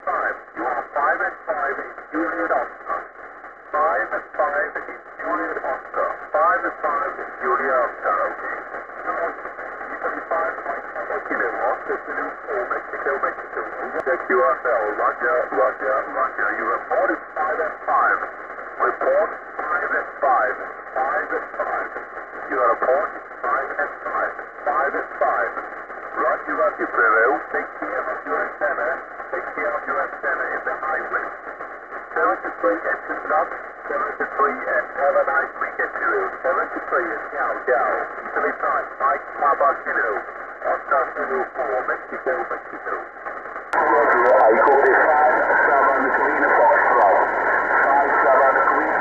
Zaraz postaram się przesłać kilka nagrań sygnałów SSB.